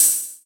edm-hihat-54.wav